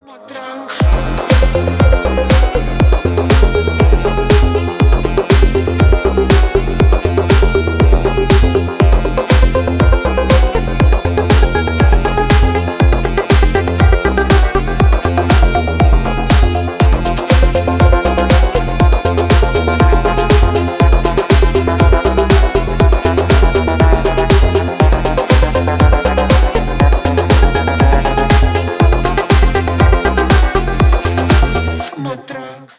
• Качество: 320, Stereo
атмосферные
индийские мотивы
Атмосферная музыка